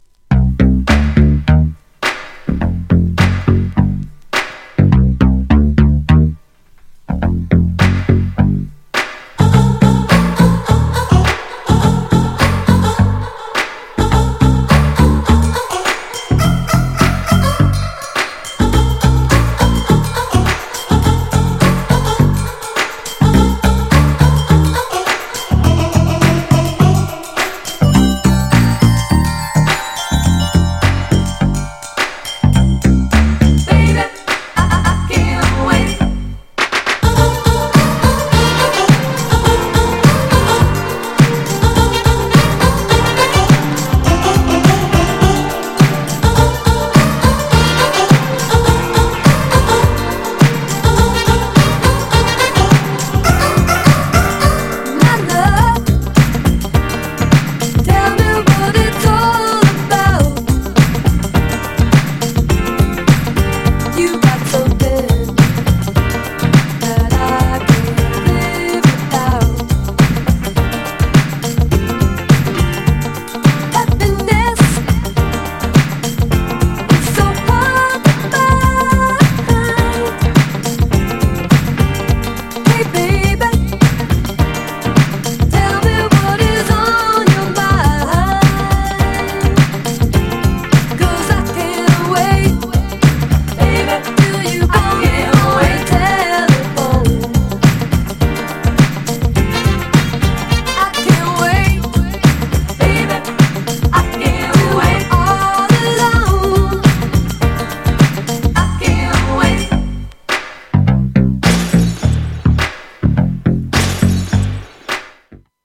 GENRE Dance Classic
BPM 126〜130BPM